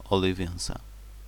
Olivenza (Spanish: [oliˈβenθa] ) or Olivença (Portuguese: [oliˈvẽsɐ]